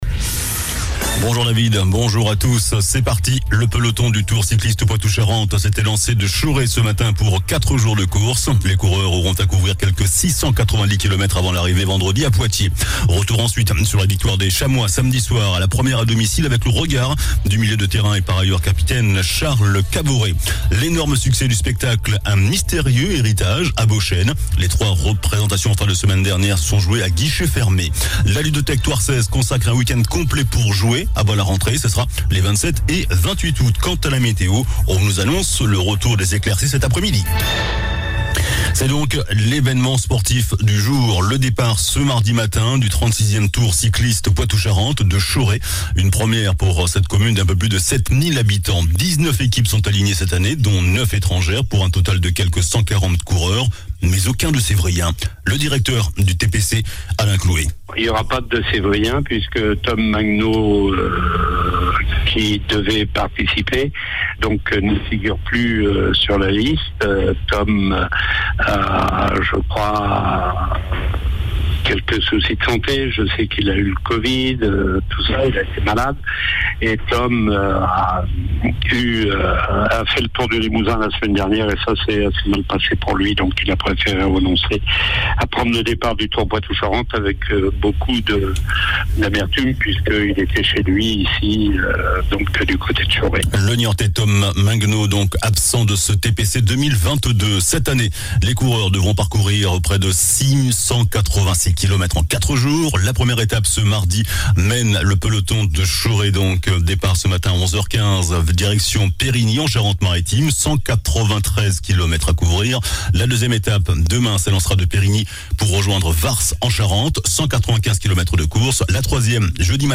JOURNAL DU MARDI 23 AOÛT